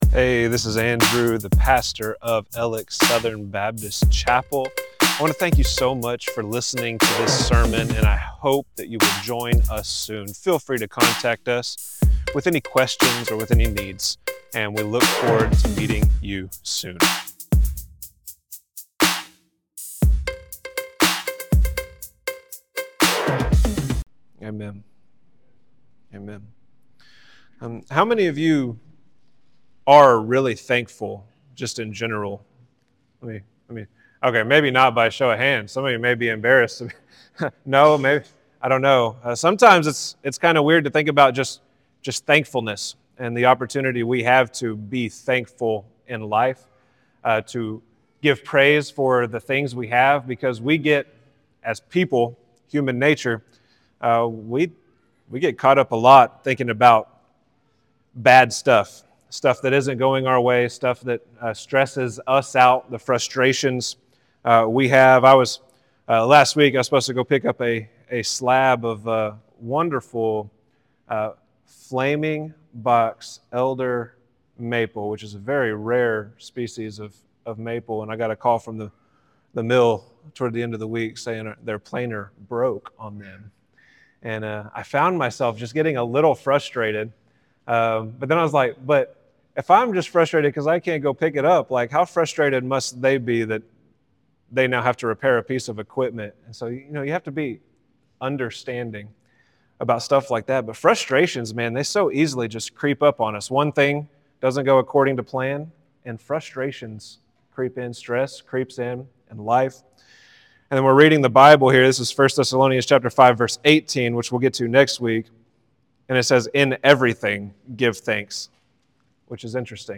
Alex Southern Baptist Chapel Sermons